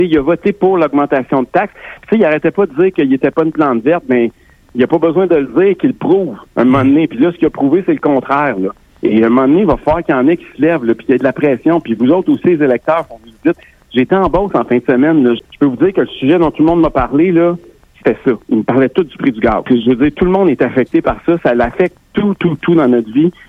Au micro de Radio Beauce, le chef du PCQ, estime qu’il s’agit d’une diversion du gouvernement Legault, alors que l’essence est 0,34 cents plus cher au Québec qu’en Ontario.